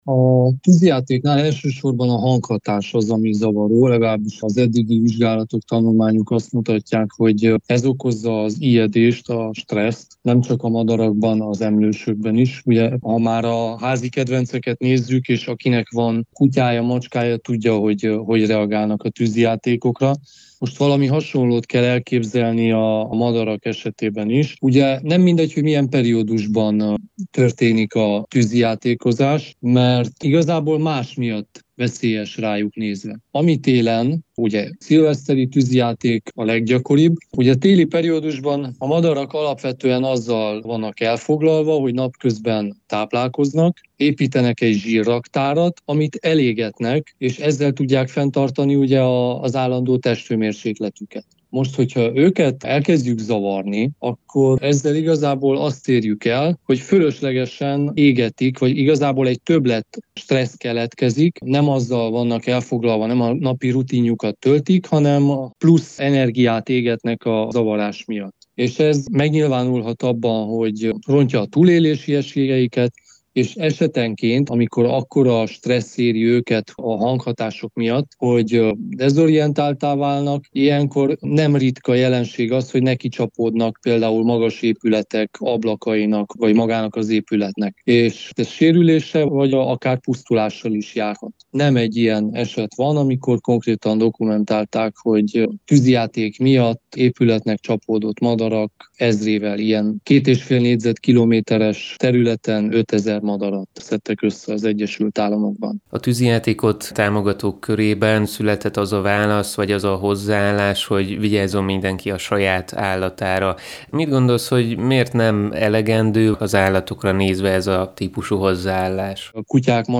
Egyre több városban váltják lézershowra a szilveszteri tűzijátékot, ennek kapcsán kérdeztünk szakembert arról, hogy milyen hatással van a különféle állatokra a durrogtatás.